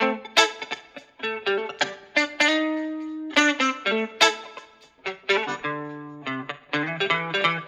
Electric Guitar 12.wav